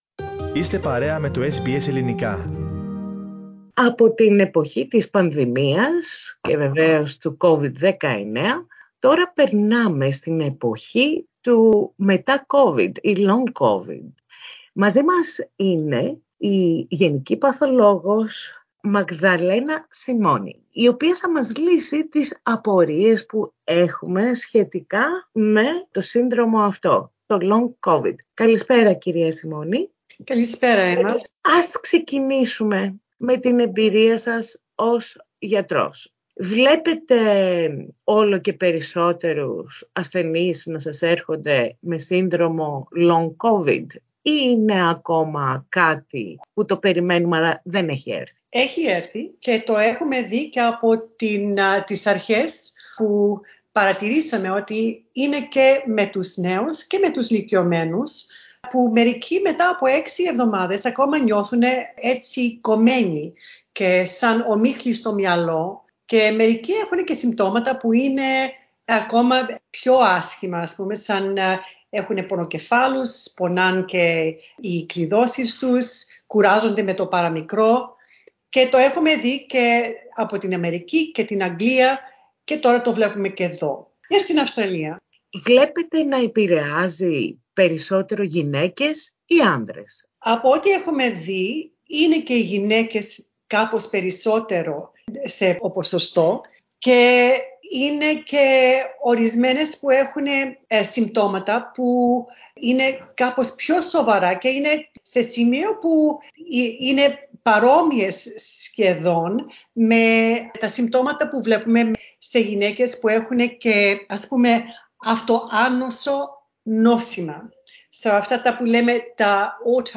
This interview is in Greek.